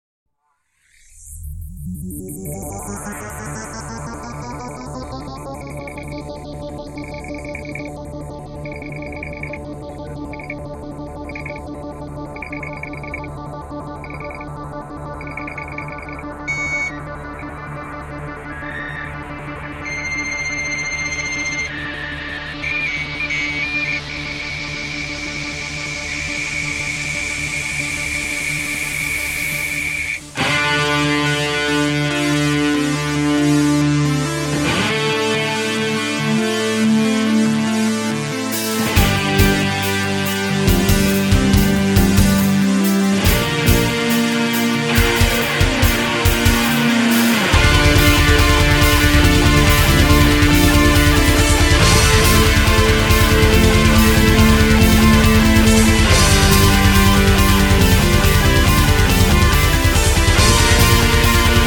Metal
трэш-метал коллектива